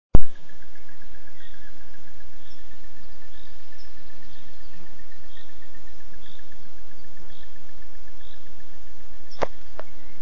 Bird Aves sp., Aves sp.
StatusVoice, calls heard
Audiofaili klusi un sliktas kvalitātes. Bet skaņa tāda kā dabā.